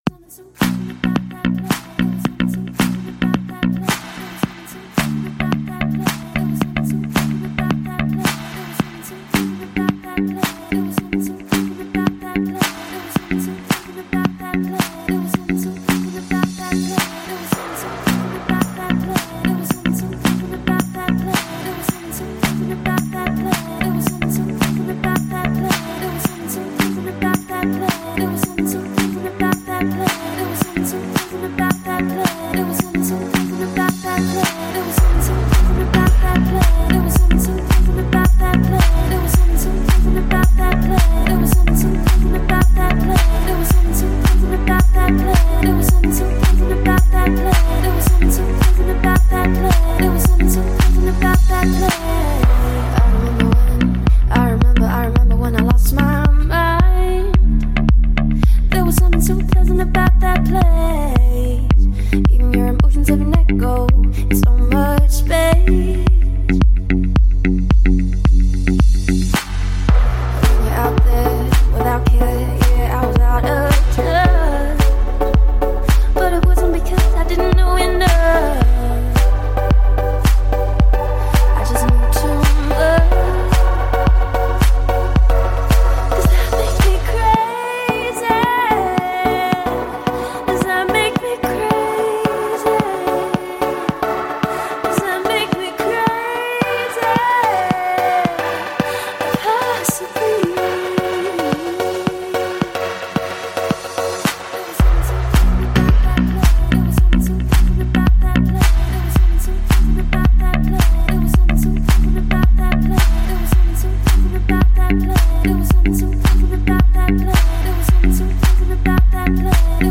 спокойная музыка
легкая приятная музыка